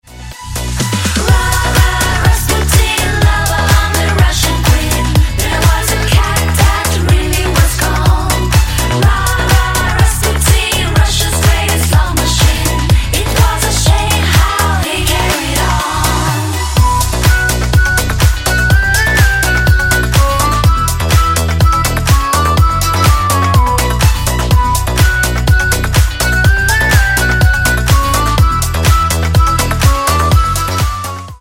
Клубные Рингтоны » # Кавер И Пародийные Рингтоны
Танцевальные Рингтоны